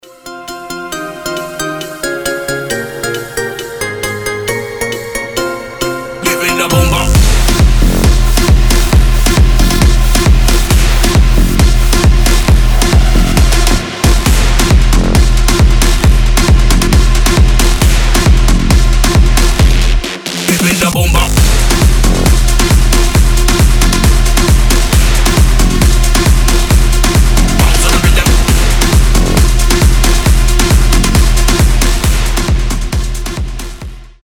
• Качество: 320, Stereo
EDM
Big Room